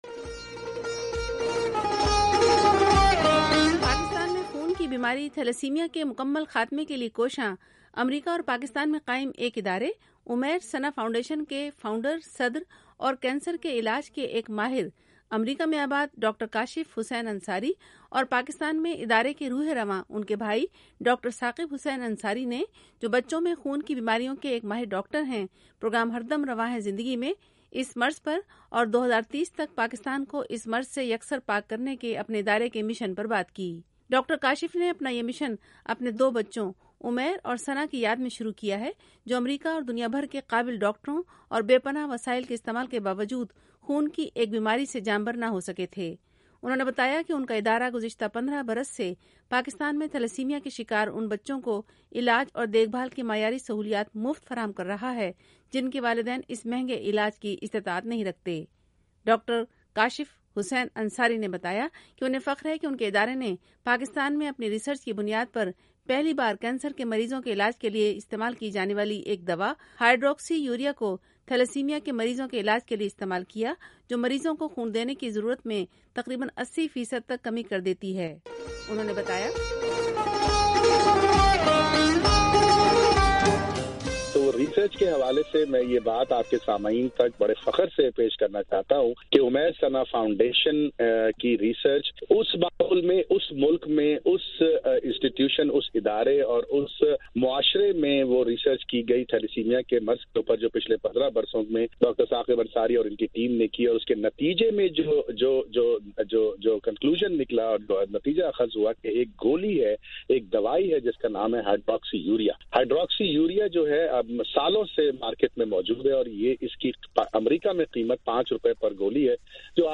انٹرویو